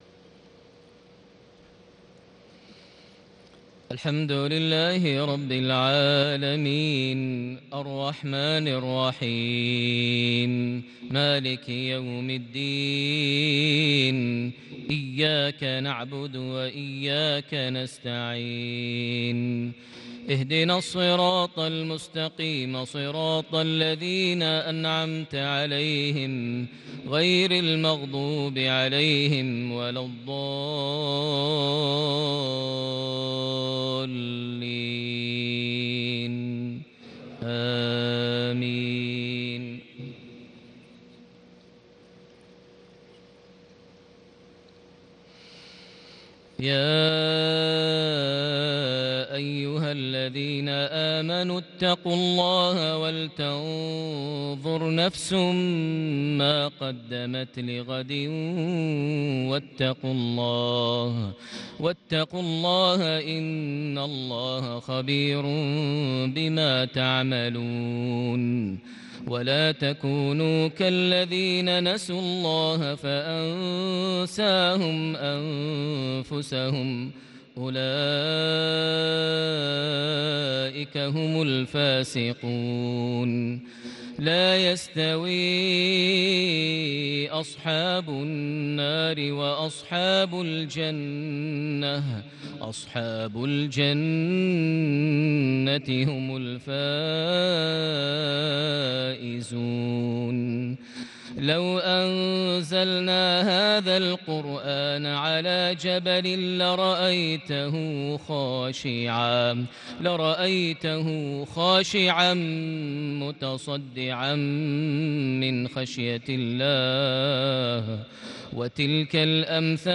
صلاة العشاء ٢١ ذو القعدة ١٤٣٨هـ خواتيم سورتي الحشر / المنافقون > 1438 هـ > الفروض - تلاوات ماهر المعيقلي